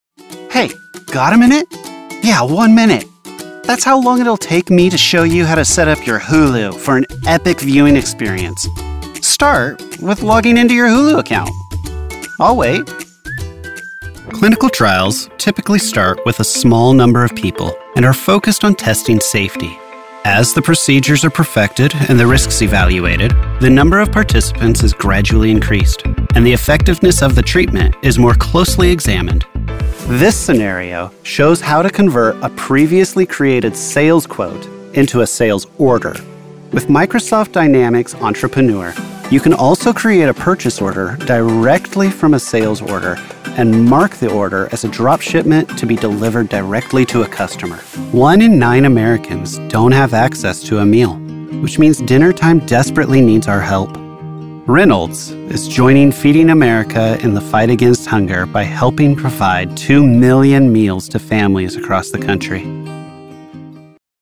Narration Demo
Voice Age
Young Adult
Middle Aged